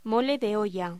Locución: Mole de olla
voz